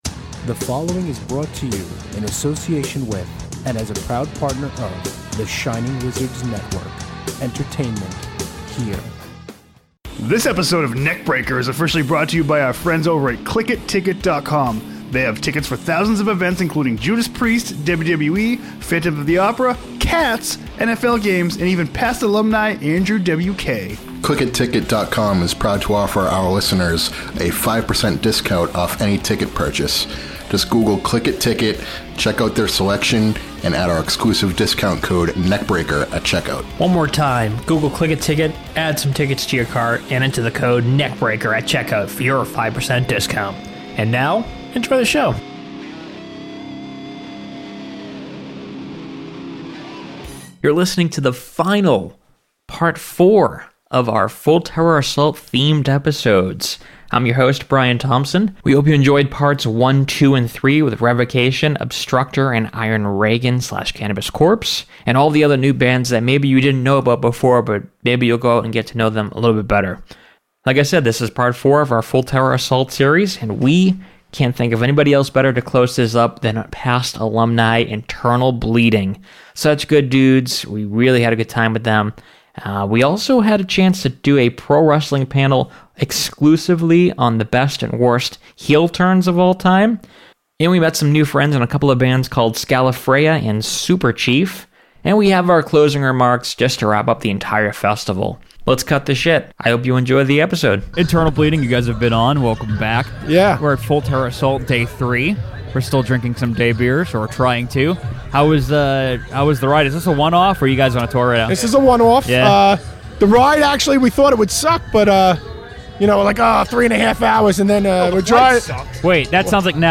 The final of the four episodes we recorded at Full Terror Assault fest with past alumni Internal Bleeding! We discuss their new album Imperium, drink some beers, and much more. Plus we host a wrestling heel turns panel, we talk worst albums by the best metal bands, and later we interview Scalafrea and Superchief.